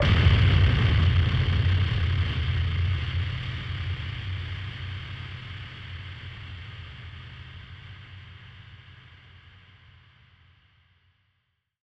BF_DrumBombD-10.wav